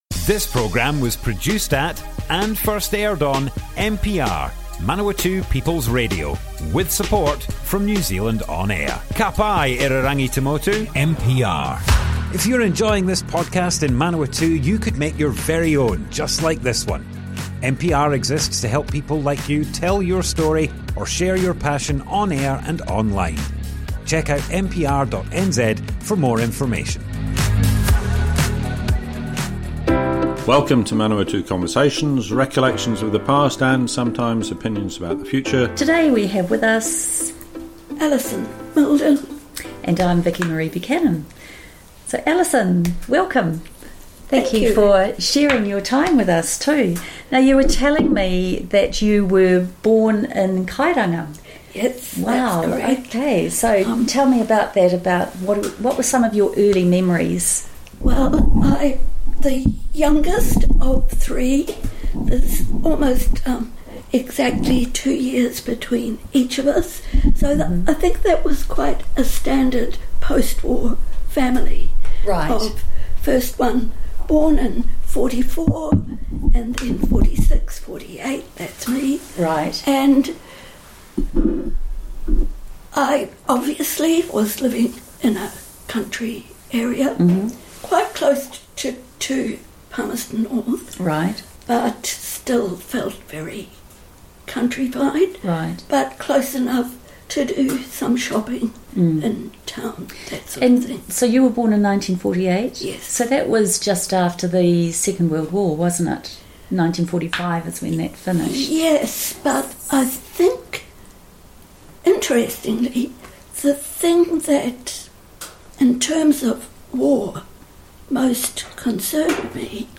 Manawatu Conversations More Info → Description Broadcast on Manawatu People's Radio, 6th August 2024.
oral history